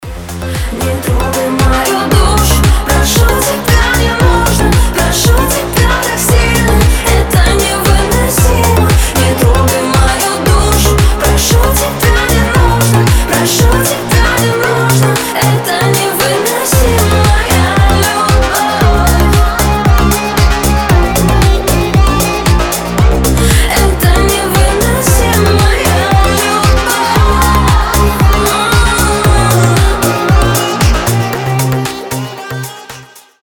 женский голос